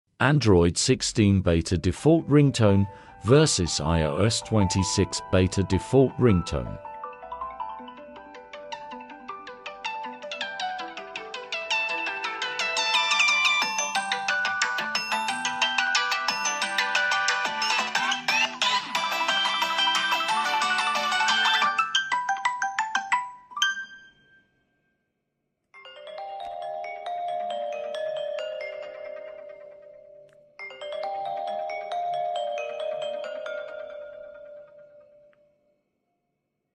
new ringtones of android 16 sound effects free download